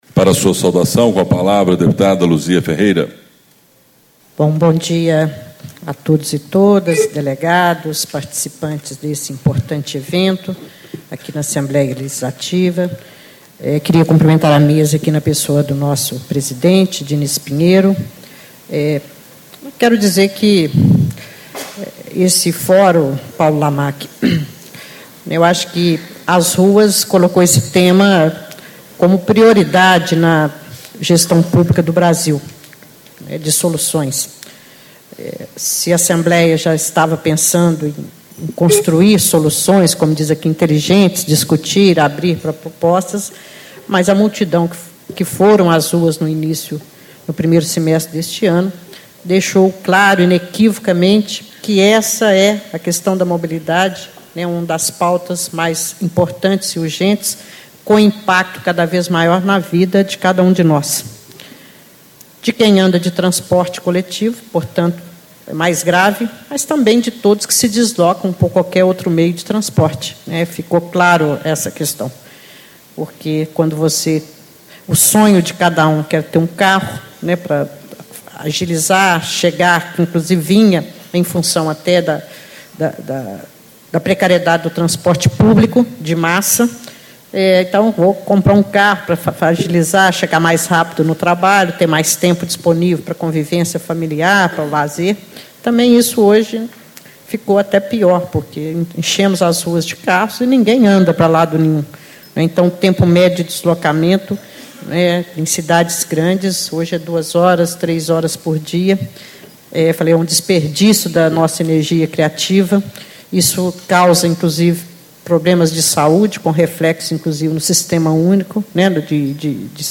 Abertura - Deputada Luzia Ferreira, PPS - Integrante da Comissão de Assuntos Municipais e Regionalização